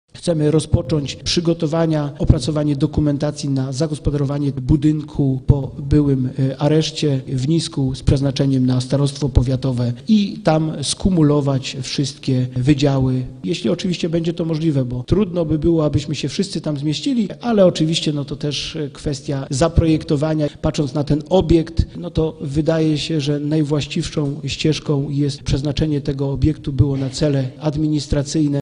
Starostwo niżańskie do budynku po areszcie śledczym chce przenieść swoje wydziały. Mówił o tym na ostatniej sesji starosta Robert Bednarz